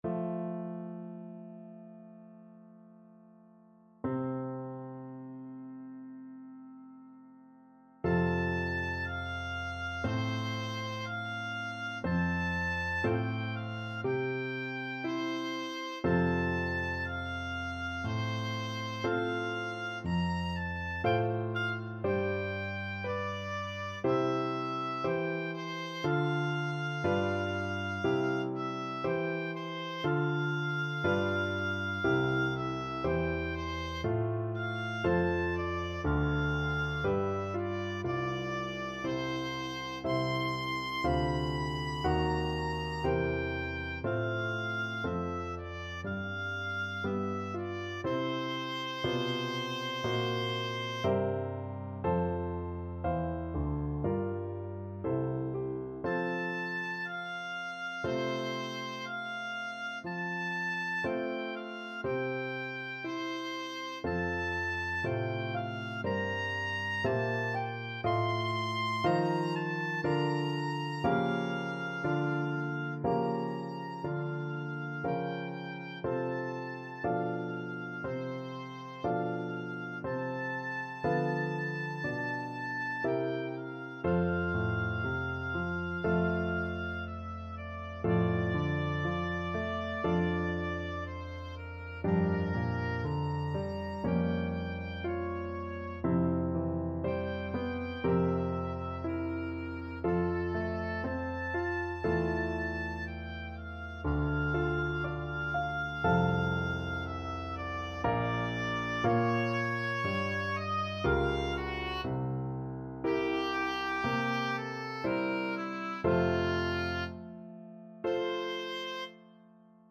Classical Brahms, Johannes Violin Concerto, Op.77, Second Movement (Main Theme) Oboe version
Oboe
2/4 (View more 2/4 Music)
~ = 100 Adagio =c.60
F major (Sounding Pitch) (View more F major Music for Oboe )
E5-C7
Classical (View more Classical Oboe Music)